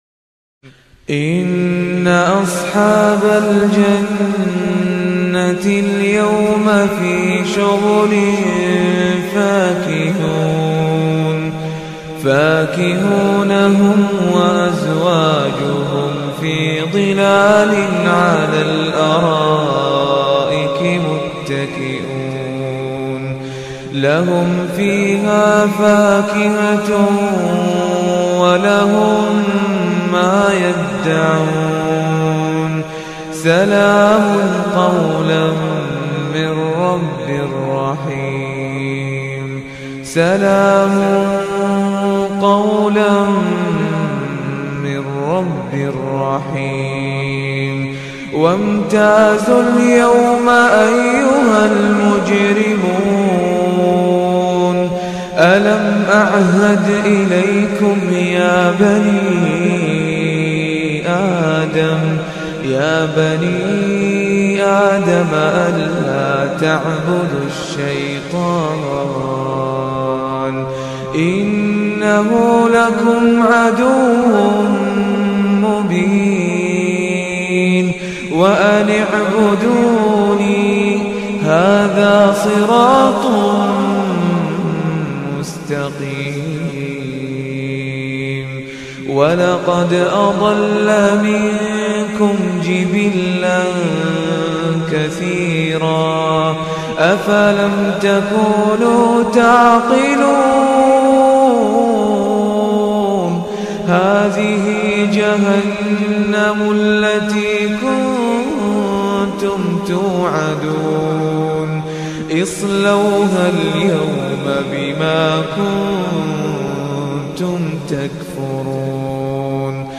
تلاوة رائعة